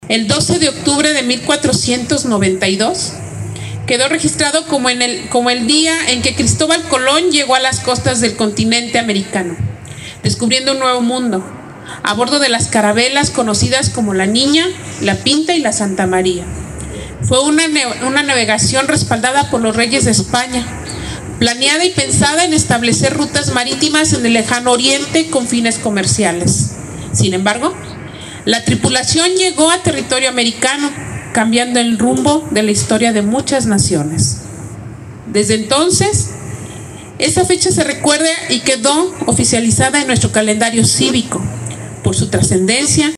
AudioBoletines
Irapuato, Gto. 12 de octubre del 2023 .- Para recordar que México es un país pluricultural, así como la importancia de respetar y trabajar en unidad por una mejor sociedad, se llevó a cabo el acto cívico conmemorativo del 12 de octubre, Día de la Raza.
Lorena Alfaro, presidenta municipal